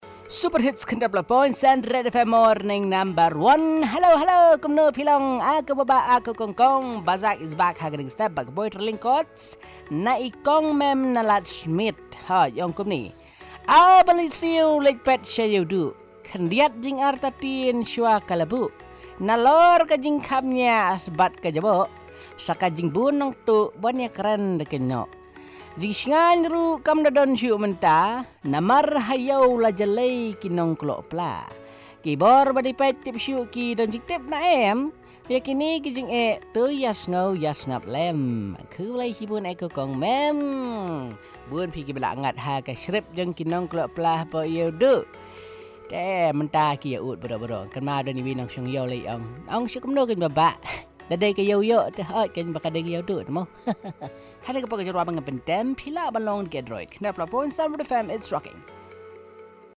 Short Poem from a listener on the local market